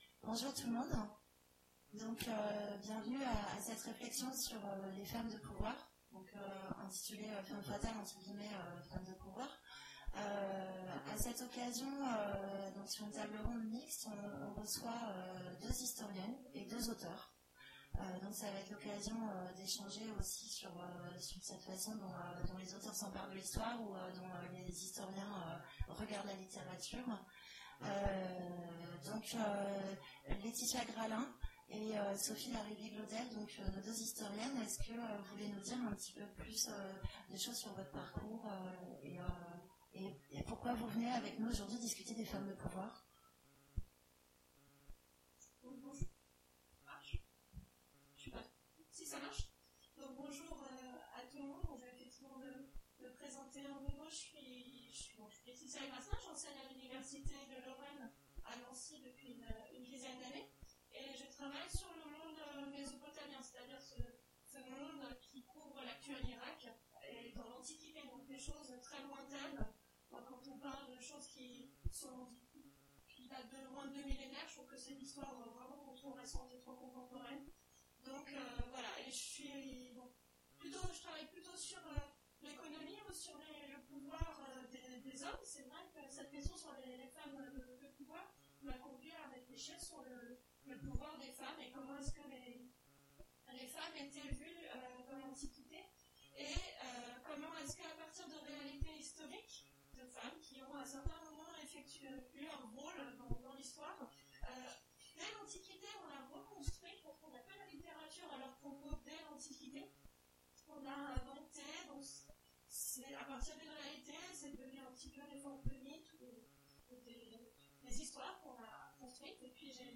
Imaginales 2017 : Conférence Femmes fatales… ou femmes de pouvoir ?